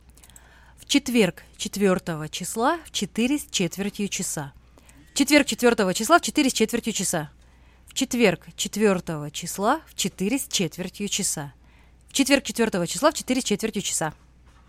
Here is a tongue-twister: